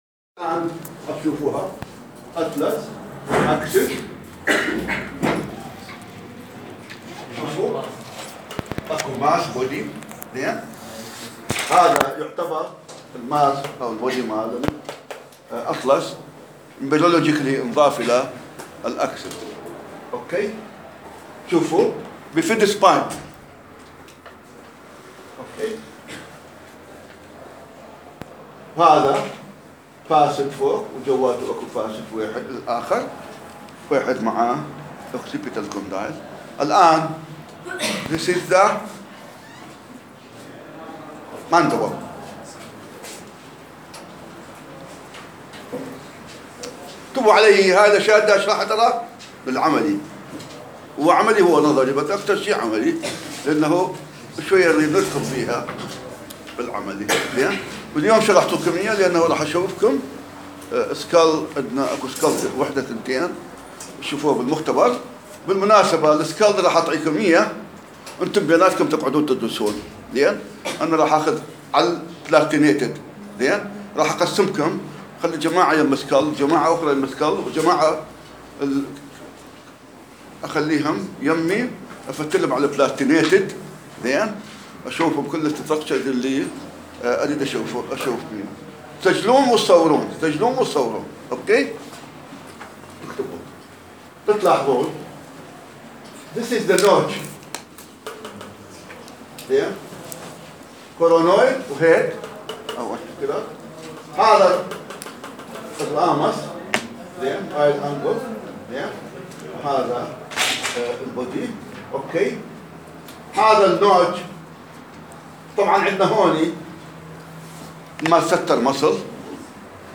التشريح > محاضرة رقم 5 بتاريخ 2015-11-10